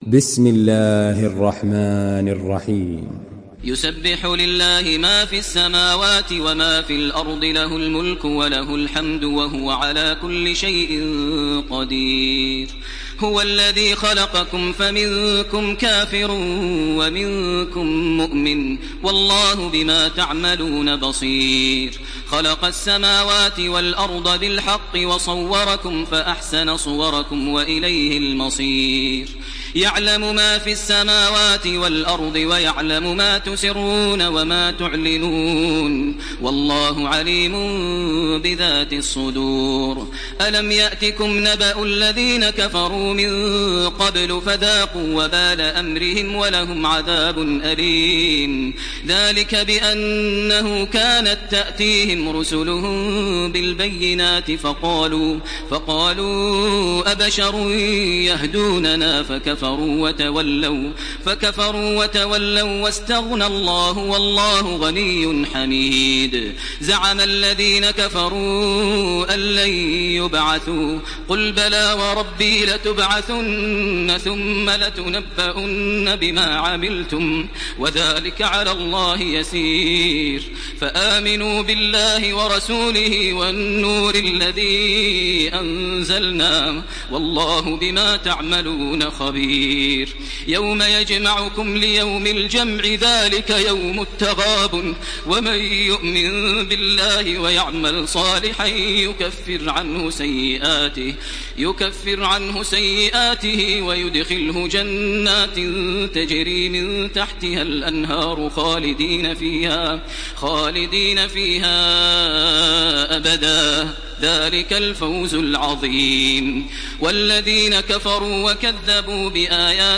سورة التغابن MP3 بصوت تراويح الحرم المكي 1429 برواية حفص
مرتل حفص عن عاصم